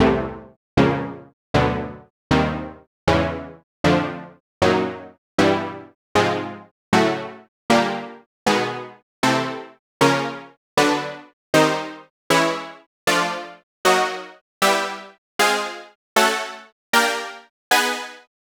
M HouseyStab.wav